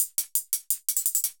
Index of /musicradar/ultimate-hihat-samples/170bpm
UHH_ElectroHatC_170-01.wav